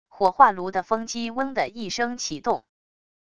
火化炉的风机嗡的一声启动wav音频